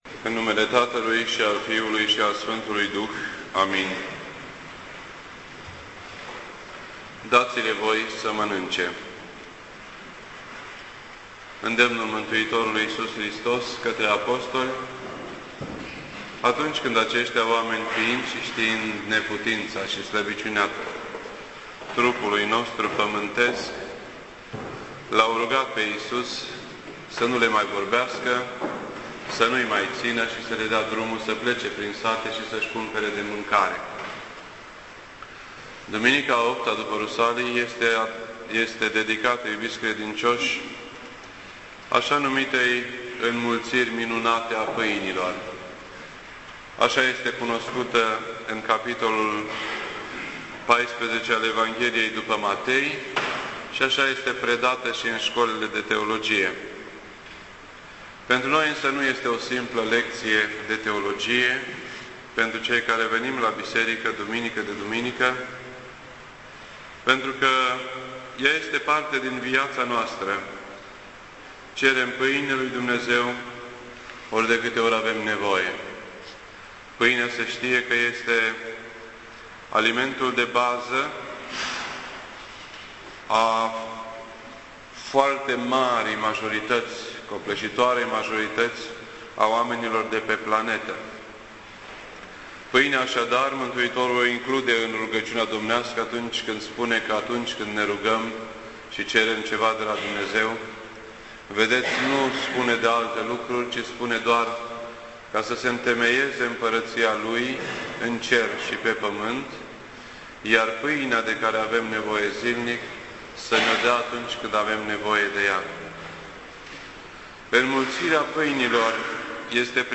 This entry was posted on Sunday, July 18th, 2010 at 9:47 PM and is filed under Predici ortodoxe in format audio.